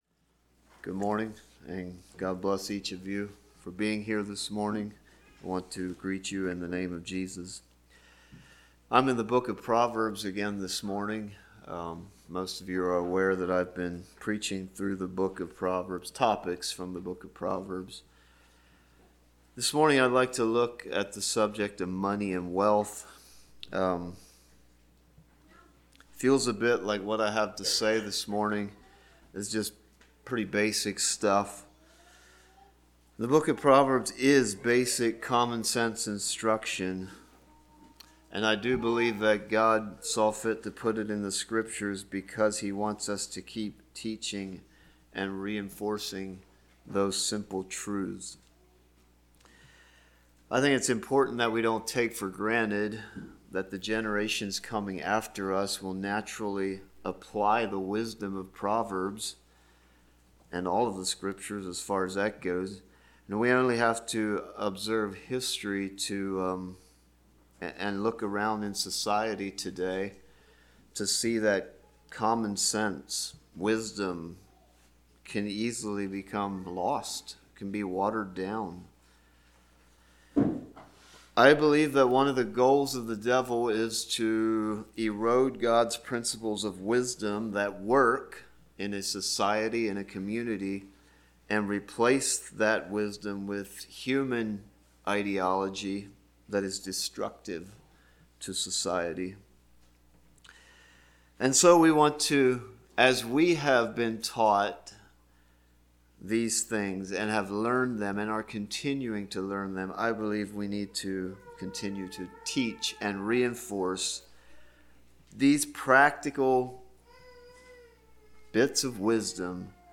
Service Type: Sunday Morning Topics: Money , Riches , Wealth « Add to Virtue